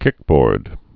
(kĭkbôrd)